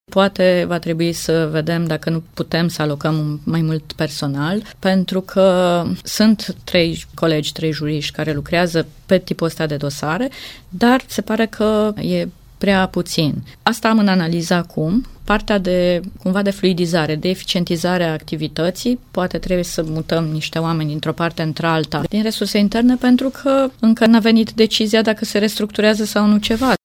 În Timiș, situația a fost deblocată prin elaborarea unui regulament propriu, însă comisia a ajuns în prezent cu soluționarea dosarelor până în anul 2022, spune prefectul Cornelia Micicoi.